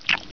chew1.wav